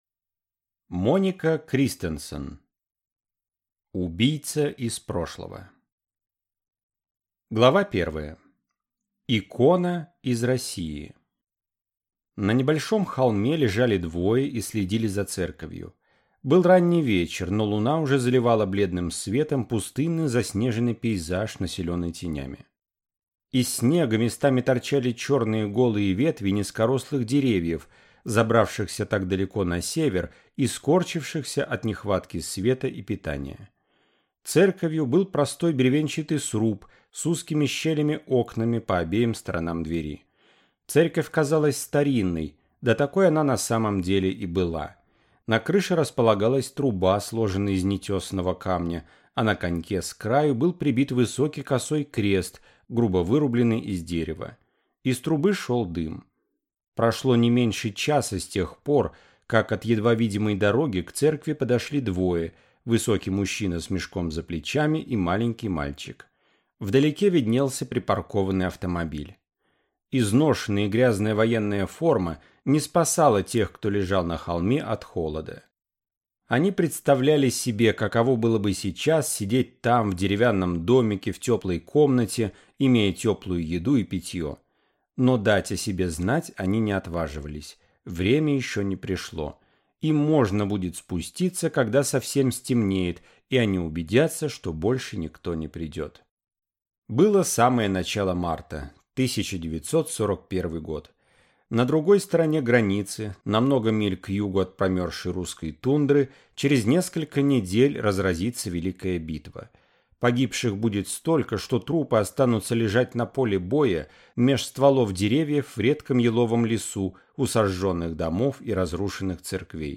Аудиокнига Убийца из прошлого | Библиотека аудиокниг